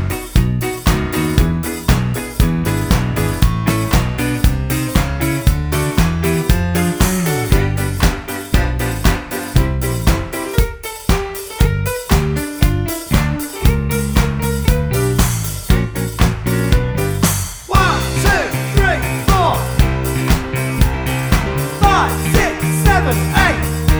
For Solo Singer T.V. Themes 2:23 Buy £1.50